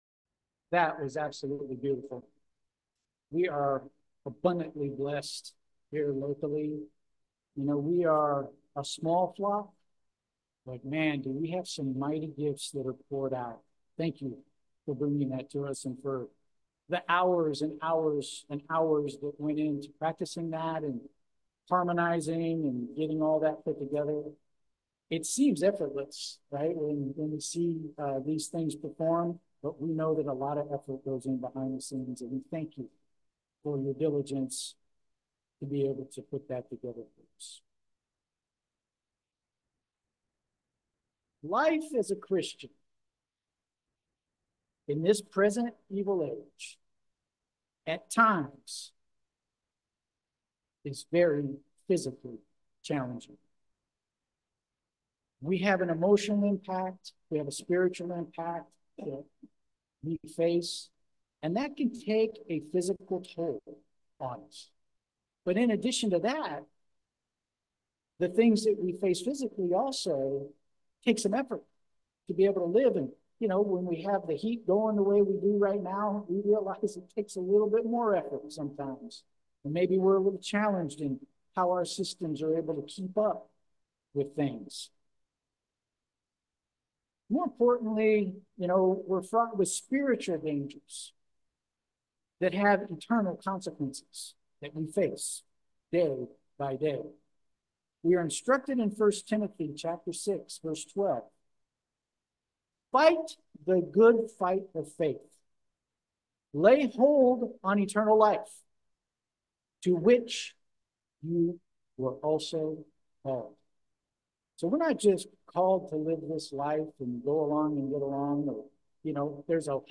Sermons
Given in Petaluma, CA San Francisco Bay Area, CA